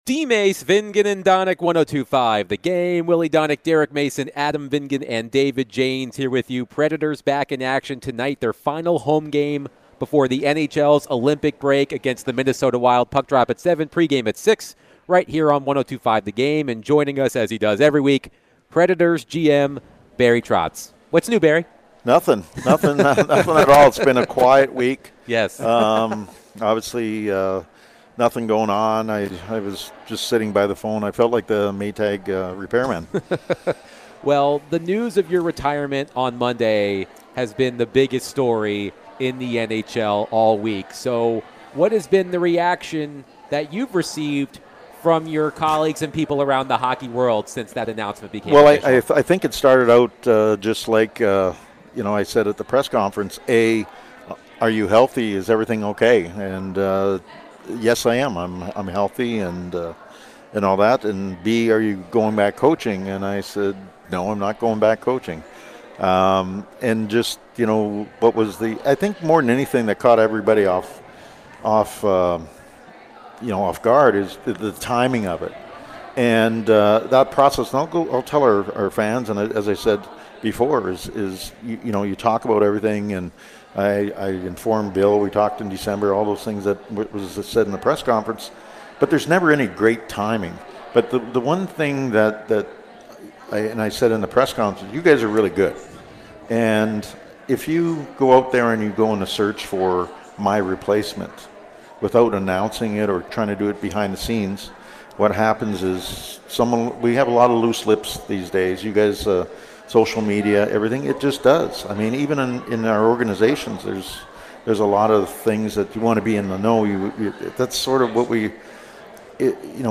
Nashville Predators GM Barry Trotz joined DVD for his weekly chat